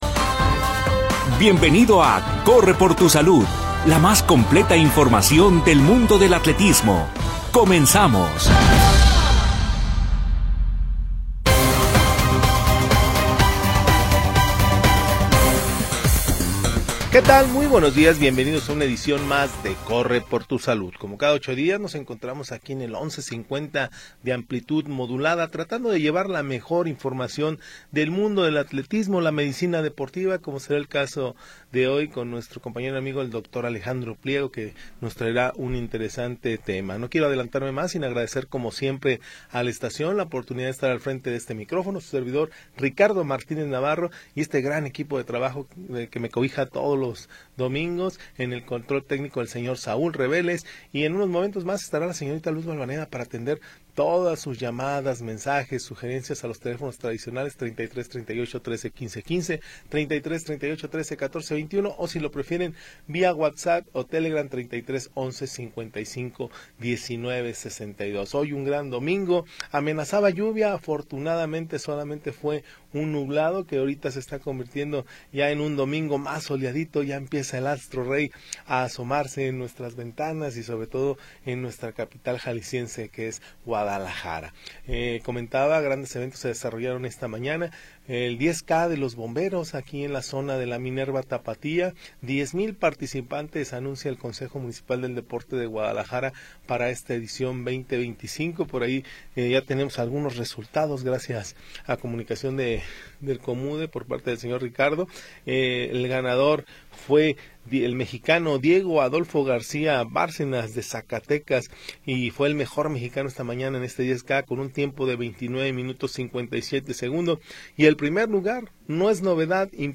Atletismo, nutrición, ejercicio sin edad. Bajo la conducción del equipo de deportes Notisistema. Programa transmitido el 24 de Agosto de 2025.